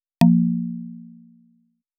error.wav